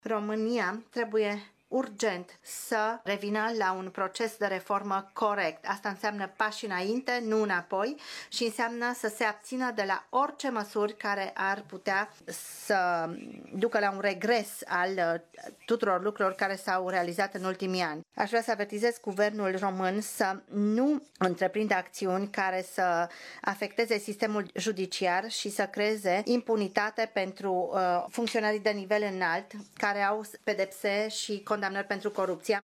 În conferința de presă susținută de Frans Timmermans  s-a vorbit despre statul de drept din România.